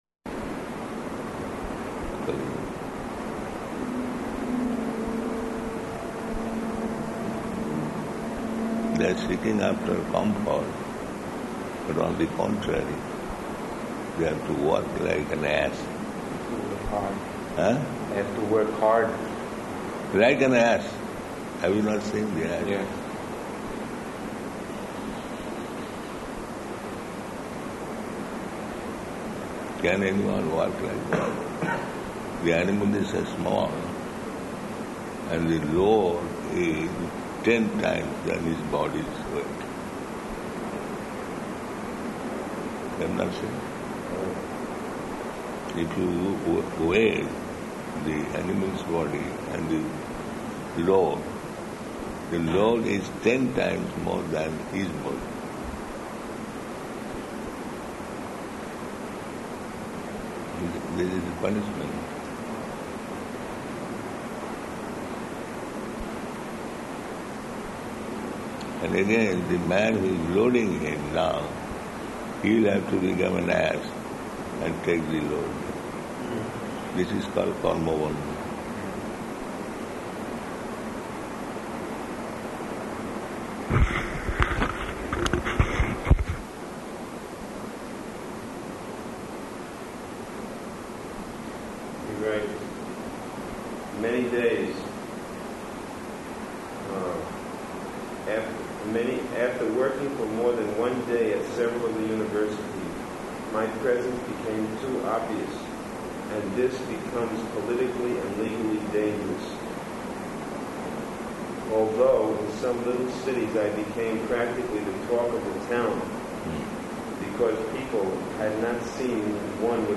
--:-- --:-- Type: Conversation Dated: June 30th 1977 Location: Vṛndāvana Audio file: 770630R3.VRN.mp3 Prabhupāda: They are seeking after comfort, but on the contrary, they have to work like an ass.